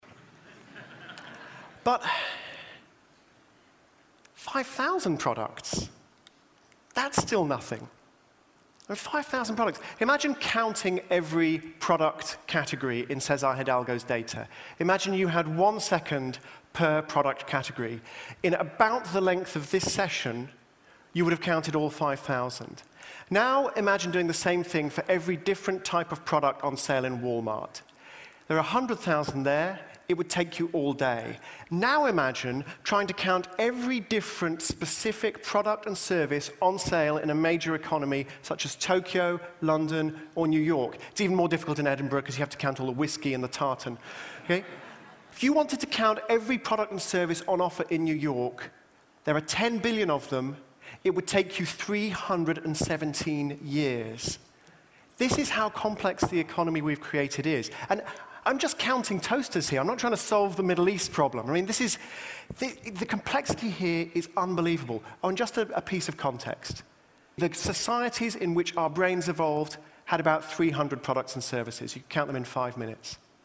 TED演讲：试验 排除错误和万能神力(5) 听力文件下载—在线英语听力室